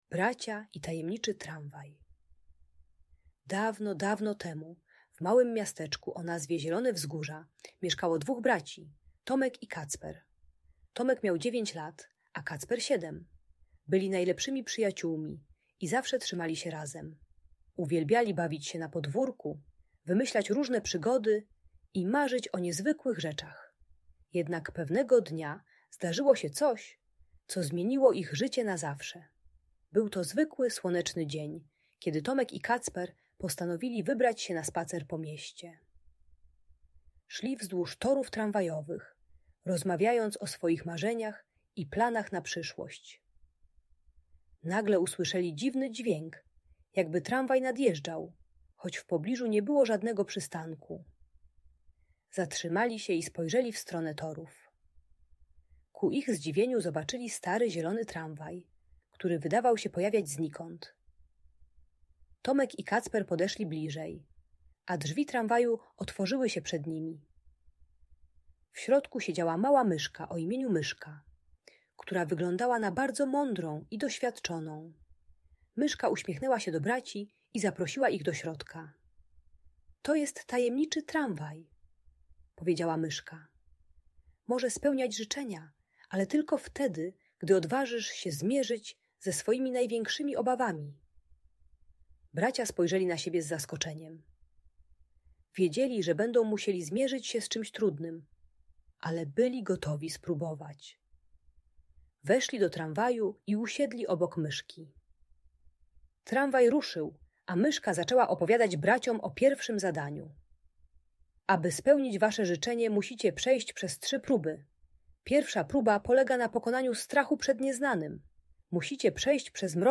Bracia i Tajemniczy Tramwaj - Lęk wycofanie | Audiobajka